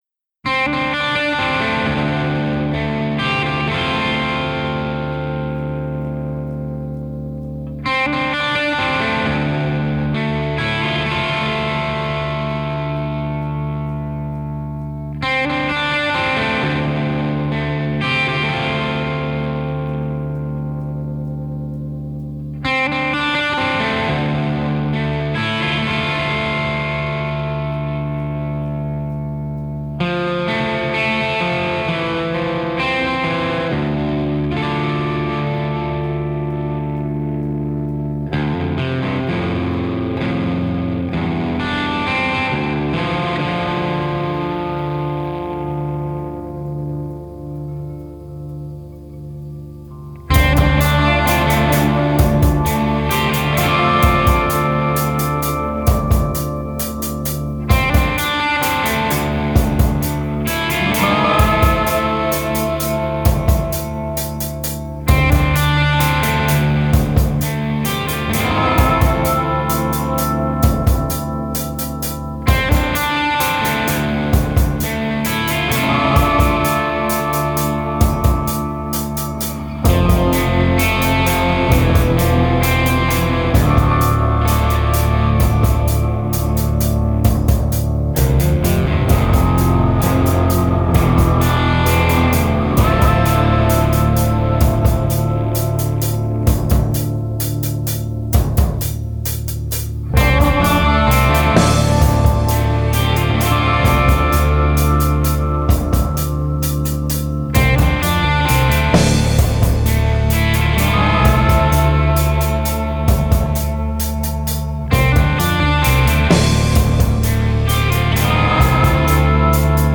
post metal